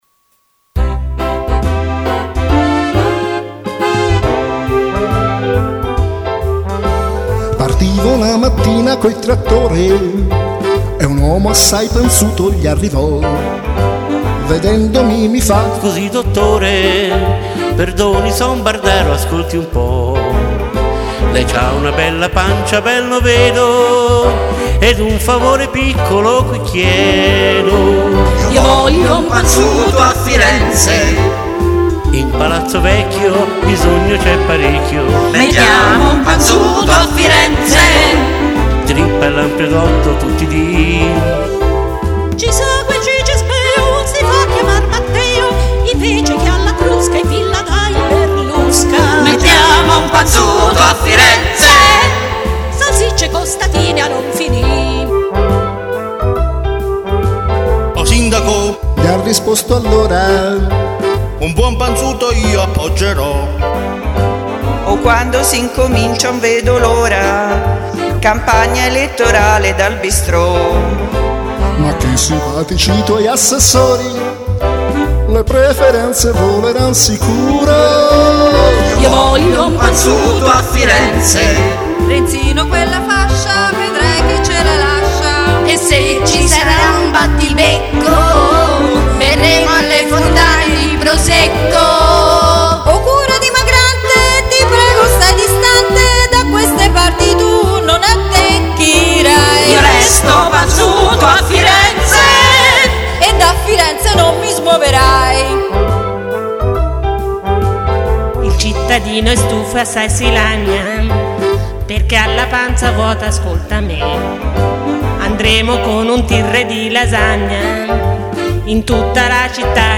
gruppo vocale
registrata da ben 12 voci!